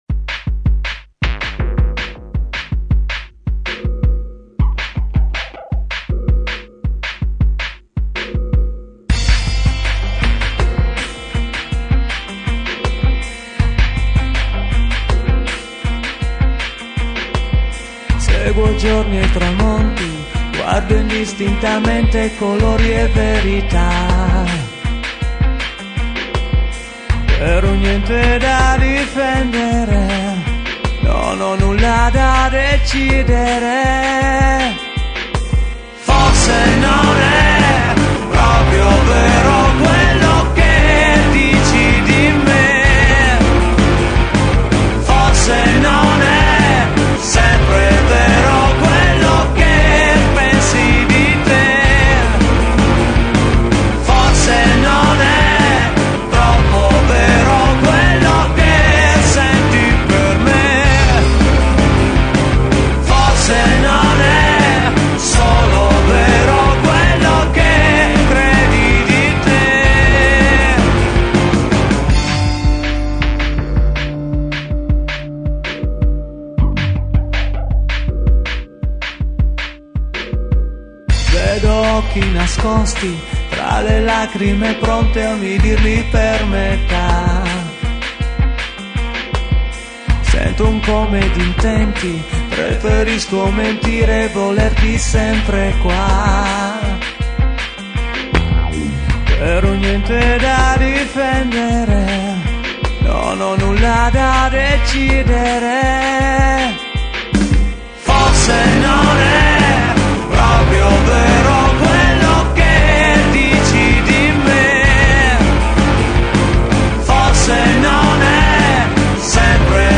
MUSIC>alternative rock
Chitarra
Basso
Voce
Batteria